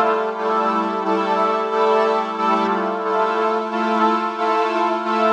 Index of /musicradar/sidechained-samples/90bpm